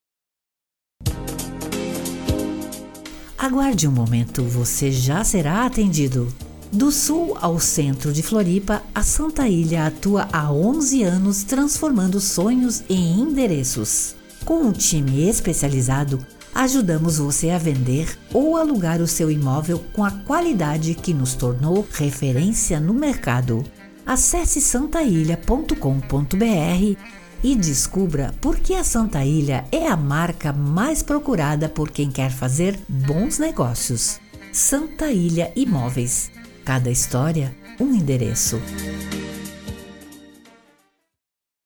VOZ FEMININA
Santa-Ilha-Centro---ESPERA---Marco-25---mp3.mp3